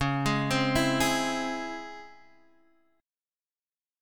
DbmM7 chord